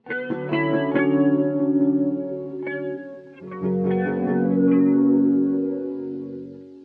描述：一个基于TR808声音的非标准节拍（避免了4踢8帽的陷阱）。
Tag: 100 bpm Trip Hop Loops Drum Loops 826.97 KB wav Key : Unknown Mixcraft